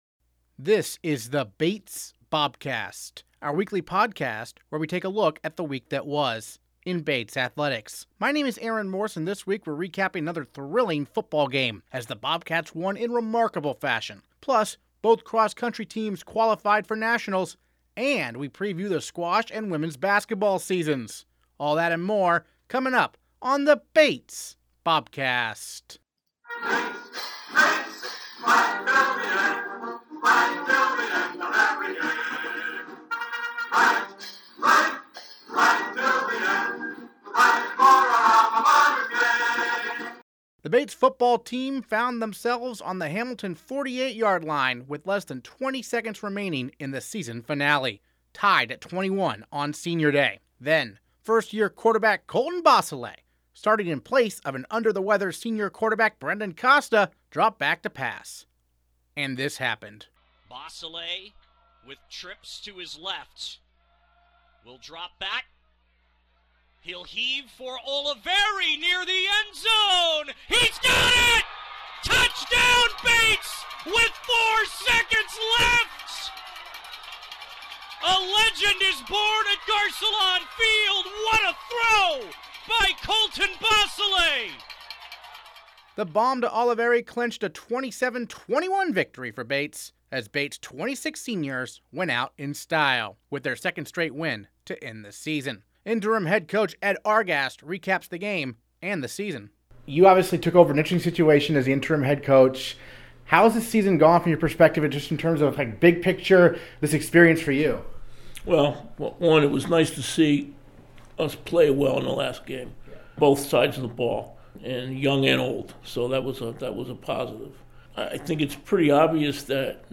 Bobcast Transcript Interviews this episode